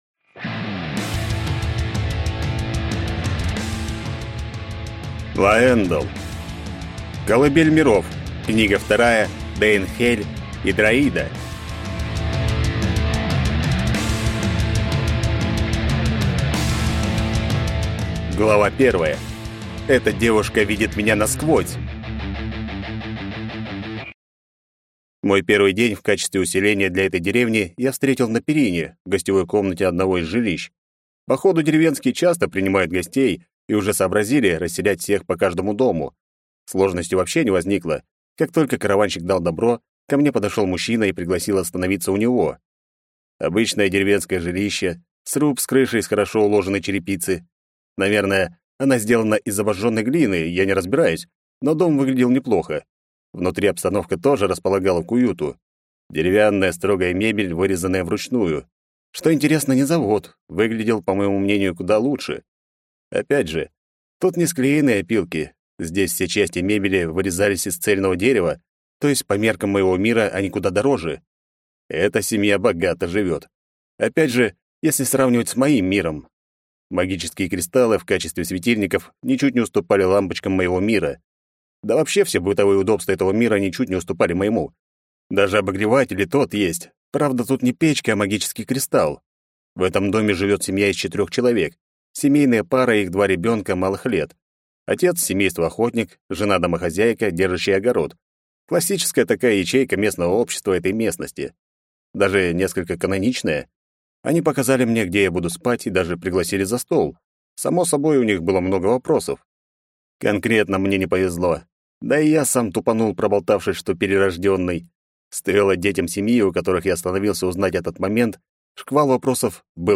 Аудиокнига Дейн'хель Идраида | Библиотека аудиокниг